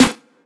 AETY_SNR.wav